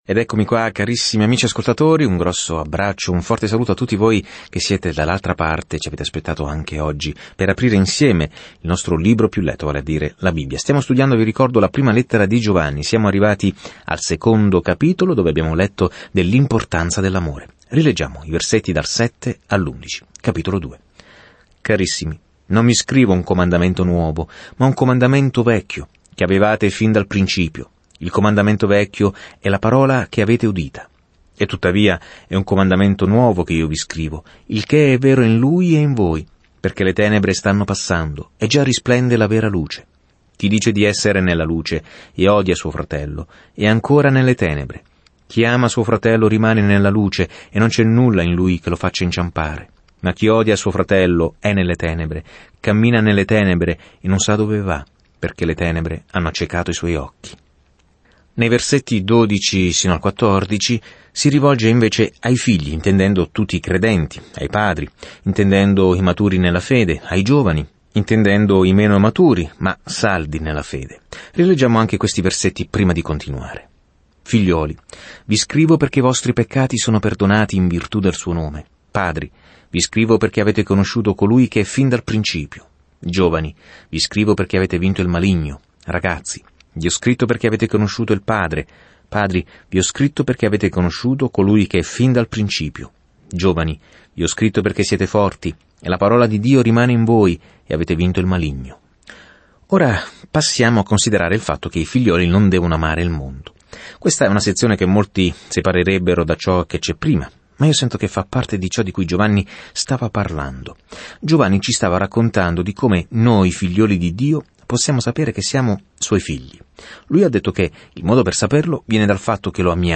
Viaggia ogni giorno attraverso 1 Giovanni mentre ascolti lo studio audio e leggi versetti selezionati della parola di Dio.